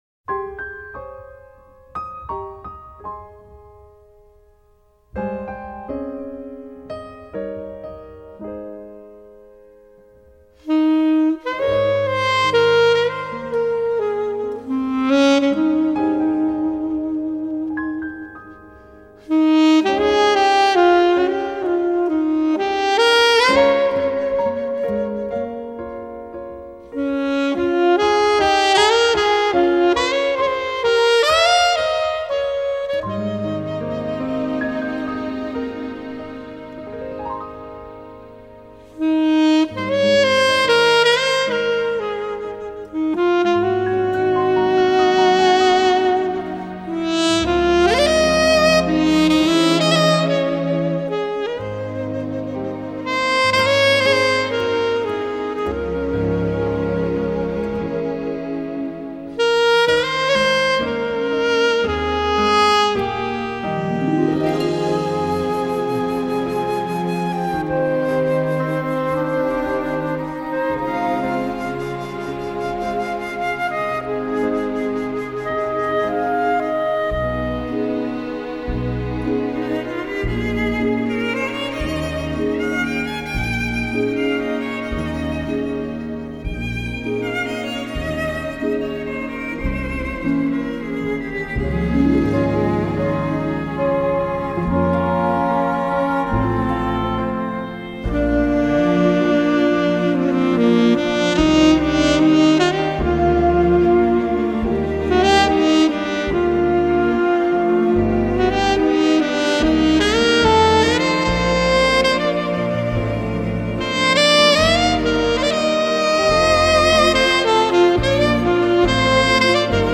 Genre: Jazz
Mixed at Capitol Studios, Los Angeles, CA.